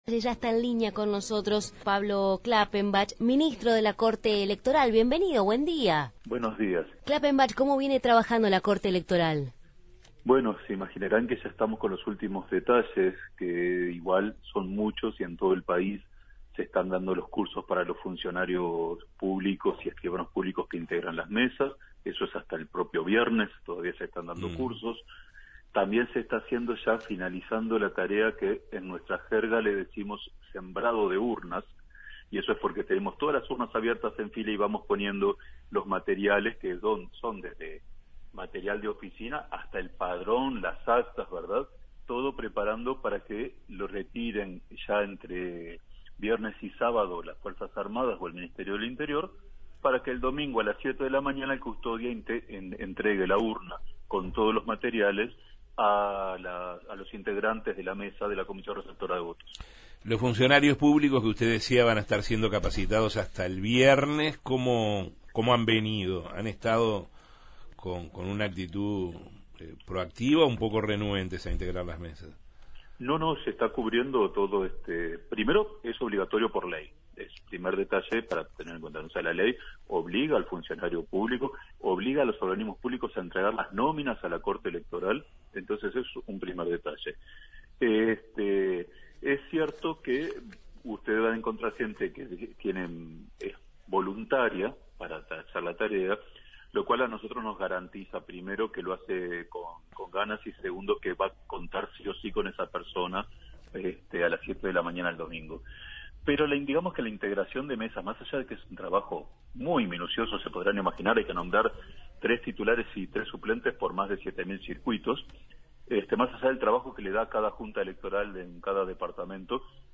Entrevista a Pablo Klappenbach, ministro de la Corte Electoral